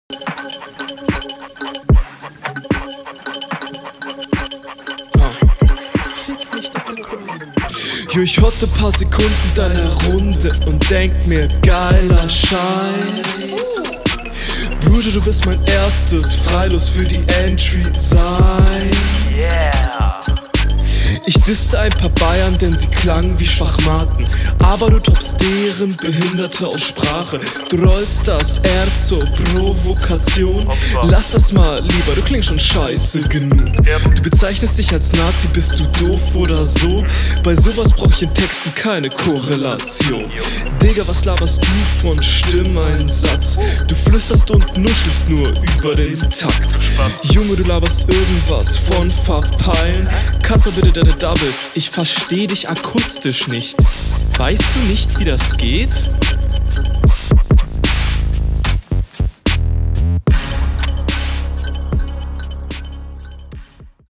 Dich versteht man deutlich besser, wobei du im Flow unterlegen bist.